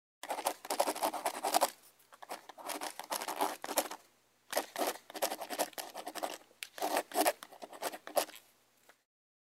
Шум шариковой ручки